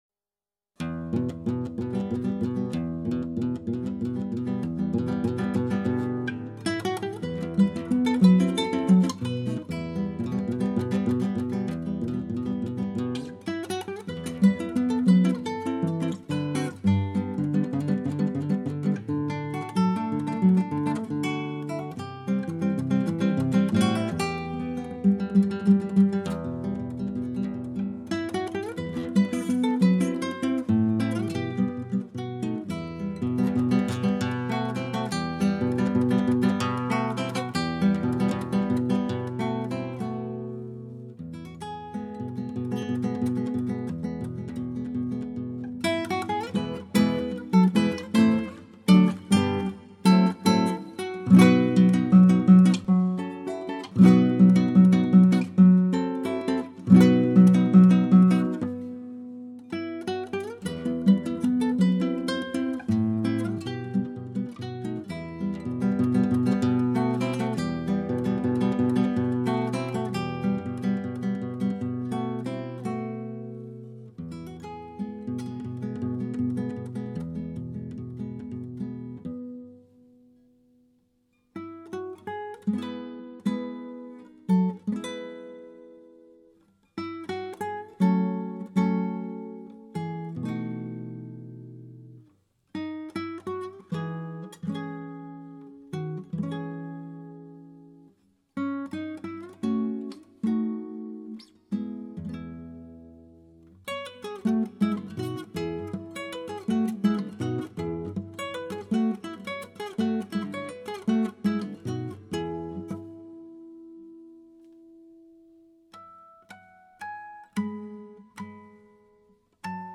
吉他独奏
吉他录音效果圆润甜美绝对空前